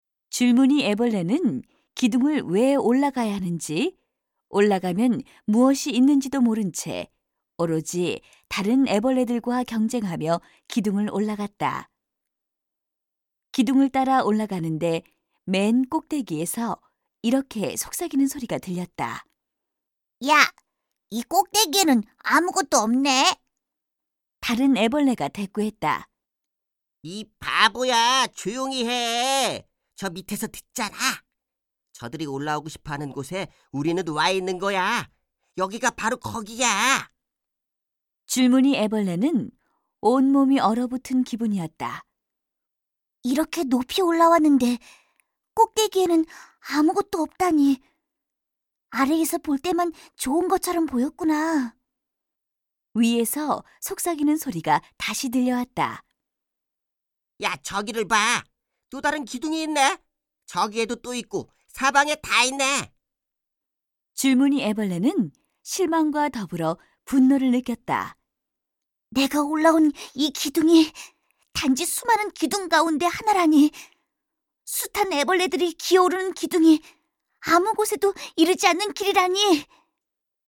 032쪽-내레이션.mp3